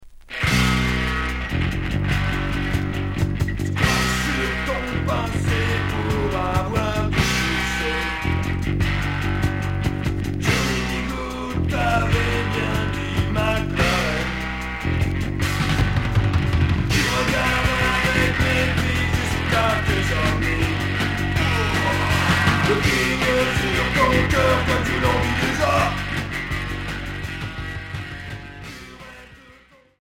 Street punk